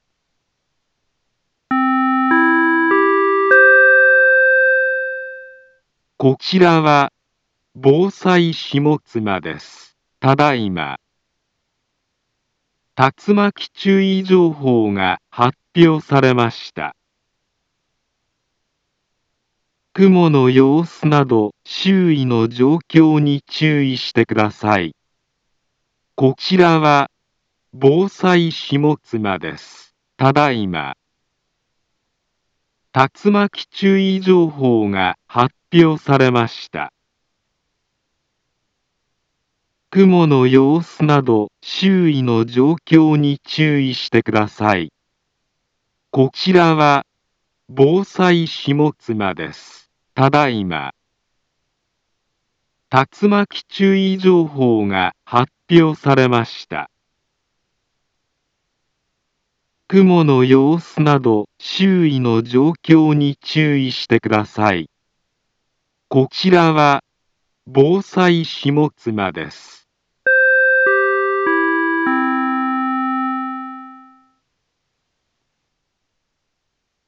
Back Home Ｊアラート情報 音声放送 再生 災害情報 カテゴリ：J-ALERT 登録日時：2022-06-03 14:25:12 インフォメーション：茨城県北部、南部は、竜巻などの激しい突風が発生しやすい気象状況になっています。